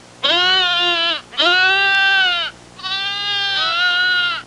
Goats Sound Effect
goats-1.mp3